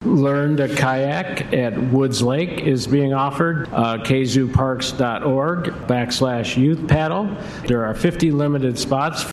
Kalamazoo City Manager Jim Ritsema says the Parks Department has a couple of events planned.